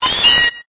Cri de Miaouss dans Pokémon Épée et Bouclier.